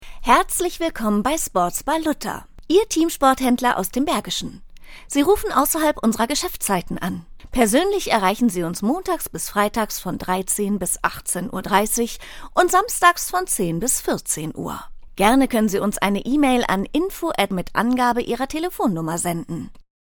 Joven, Amable, Llamativo, Versátil, Suave
Telefonía